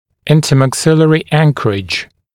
[ˌɪntəmæk’sɪlərɪ ‘æŋkərɪʤ] [ˌинтэмэк’силэри ‘энкэридж] межчелюстная опора, межчелюстная анкеровка